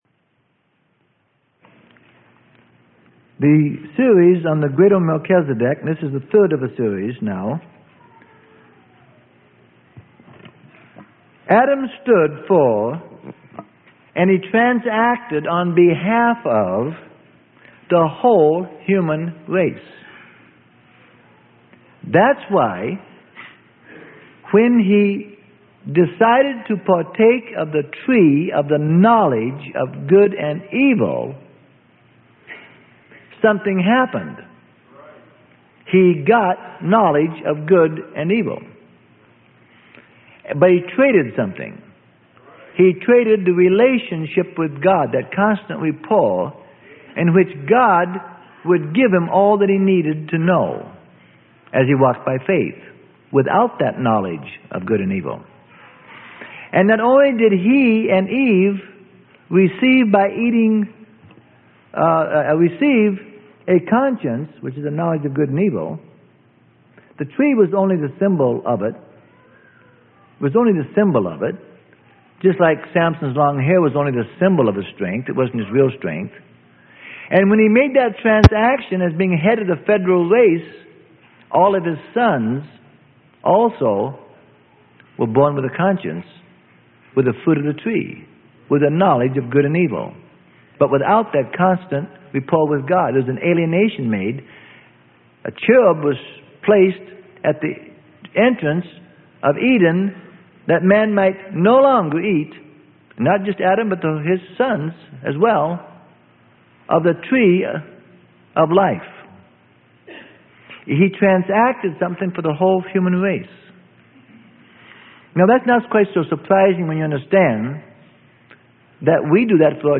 Sermon: The Greater Melchizedek - Part 3 - Freely Given Online Library